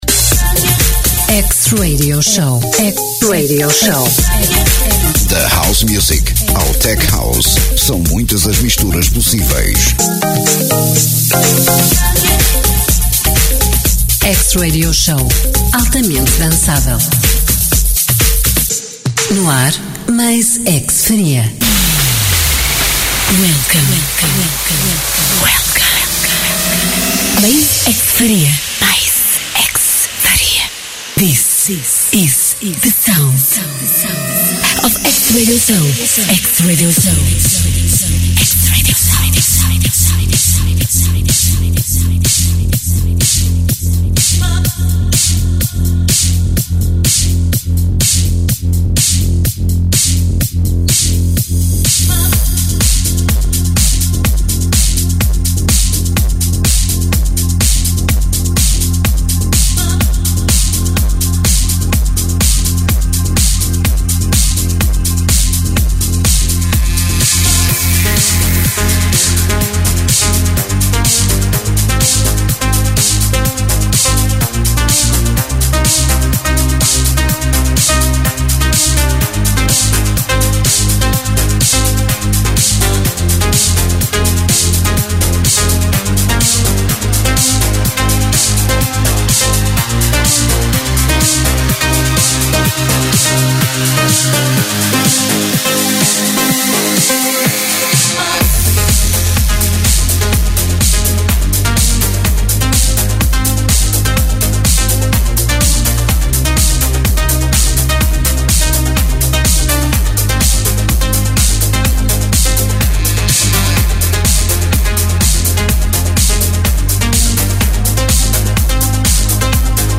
House Music in the mix